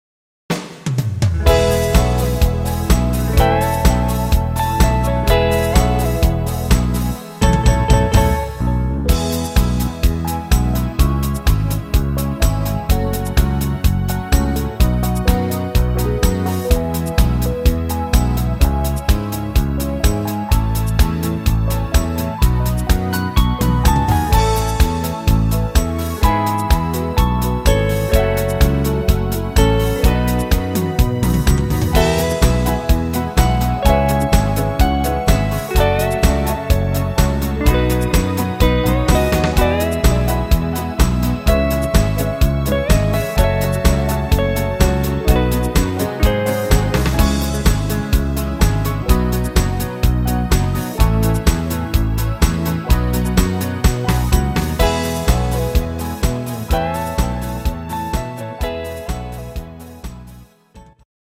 flottes Weihnachtslied